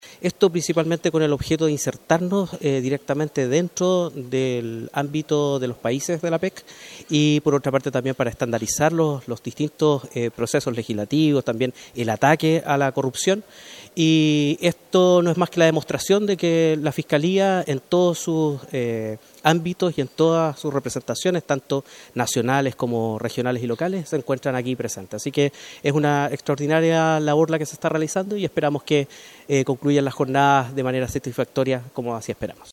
El titular de la Fiscalía de la capital regional, dio cuenta del objetivo de la participación de los fiscales en el Grupo de Trabajo Anticorrupción y Transparencia del foro.